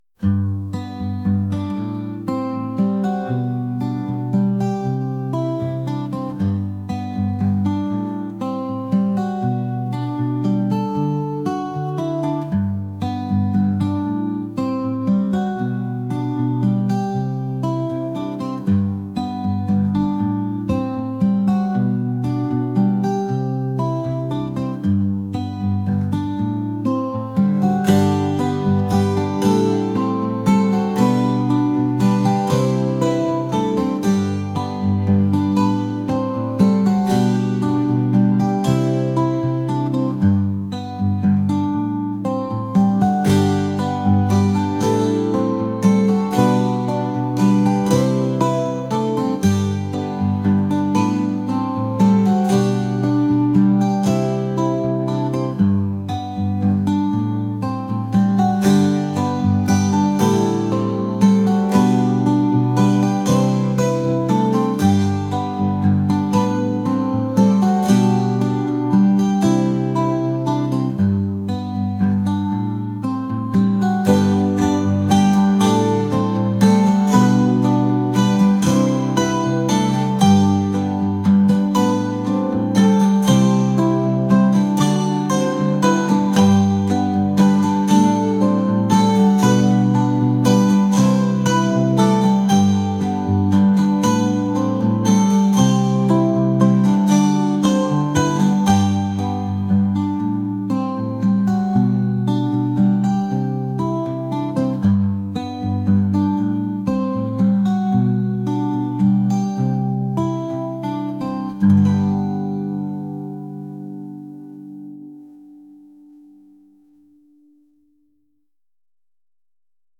indie | folk | acoustic